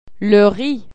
Le restaurant   hâng bâ-ee